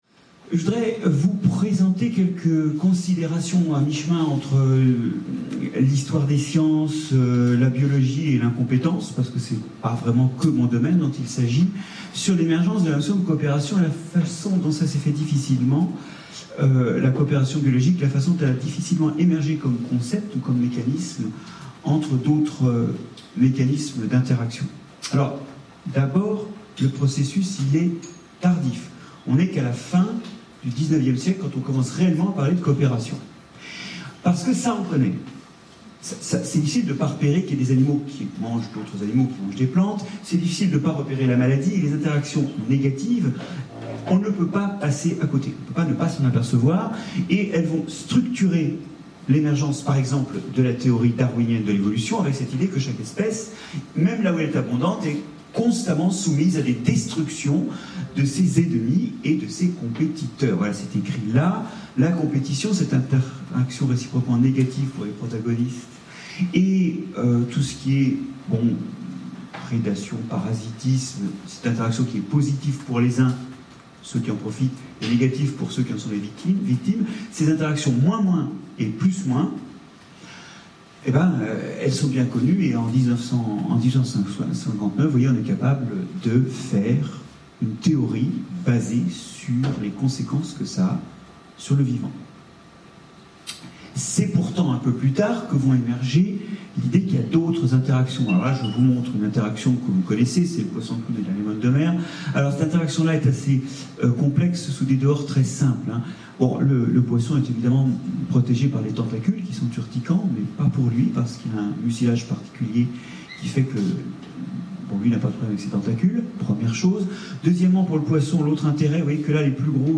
Conférence introductive - Marc-André Selosse - La coopération biologique, un dur chemin en marge de la compétition et de la prédation.